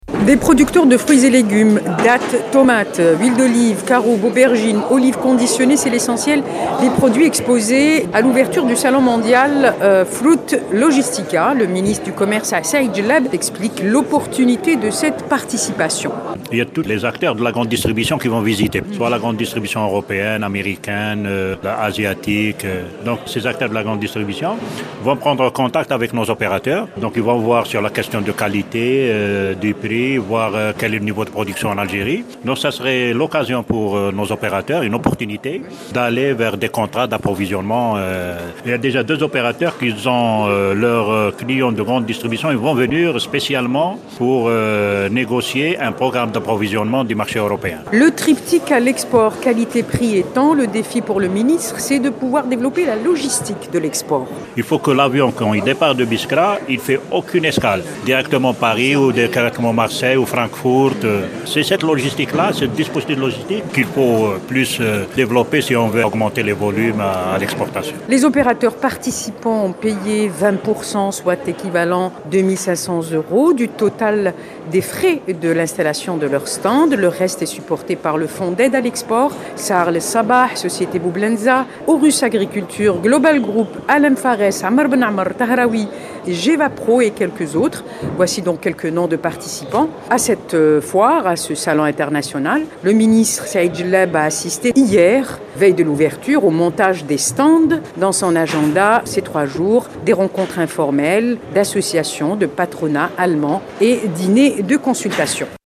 Said Djellab, ministre du commerce au micro de al radio Chaine 3